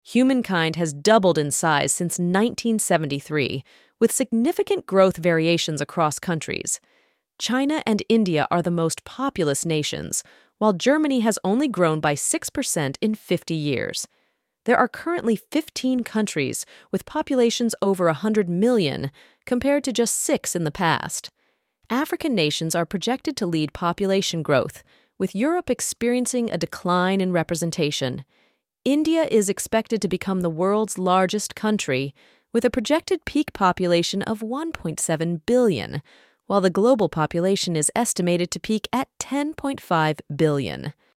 Quick Summary Audio